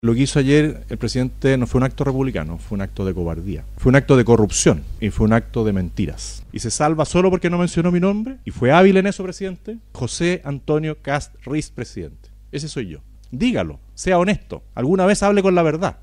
Frente a los dichos del jefe de Estado, el propio Kast, en medio de en un punto de prensa, lanzó sus dardos a La Moneda, asegurando que nunca en la historia republicana había visto algo como lo de anoche.